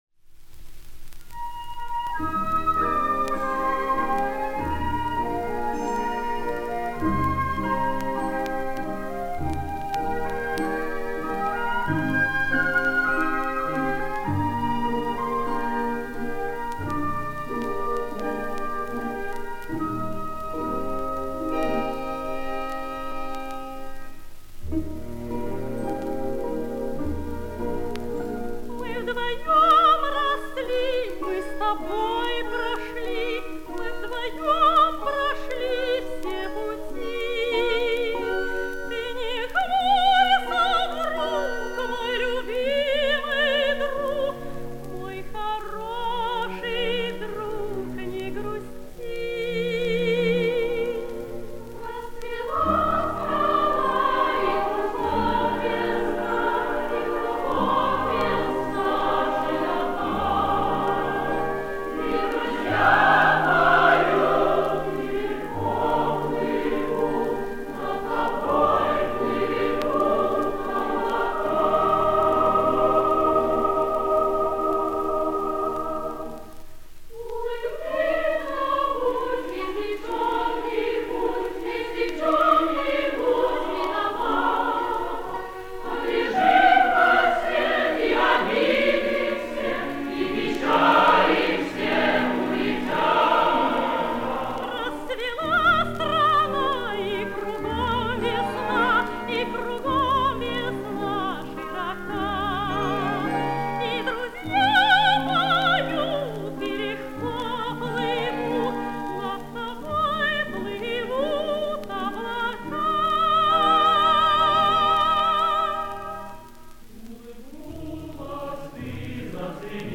Прекрасная довоенная лирика в достойнейшем исполнении.